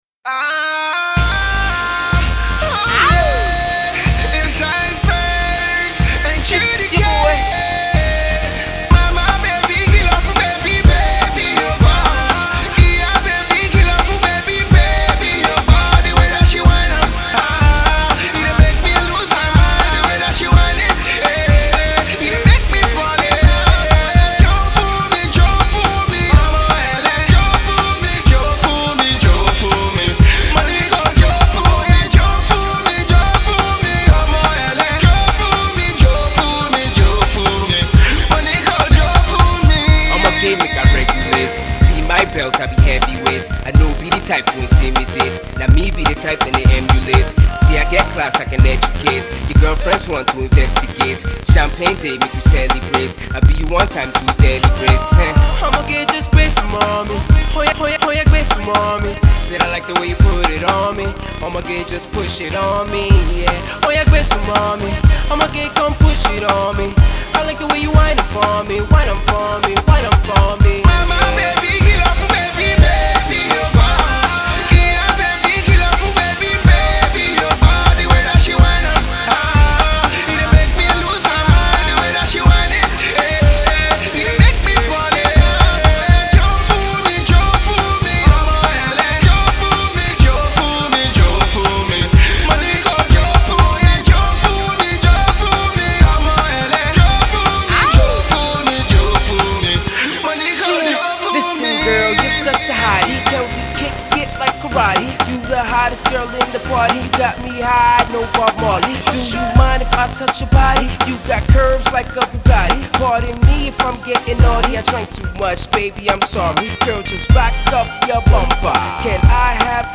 club banger
on a beat heavy
crooning on this one